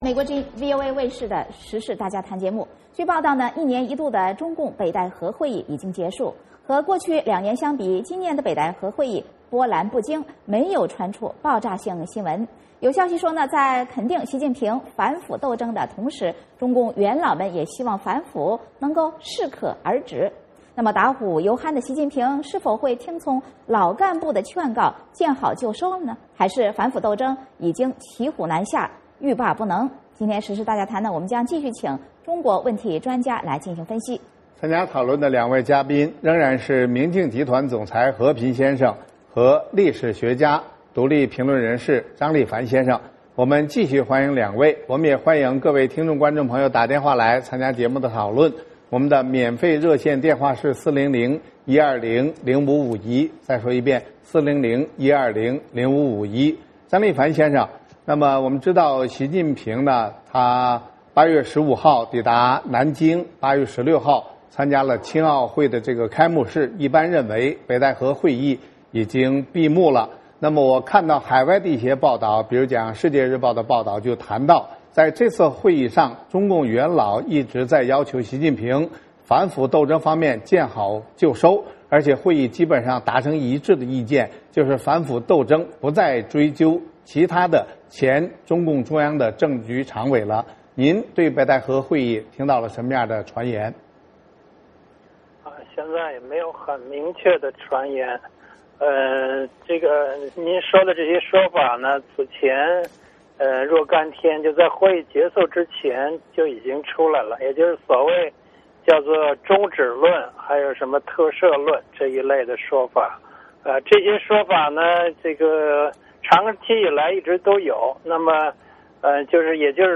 时事大家谈继续请中国问题专家分析。